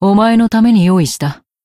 BA_V_Mina_Battle_Shout_2.ogg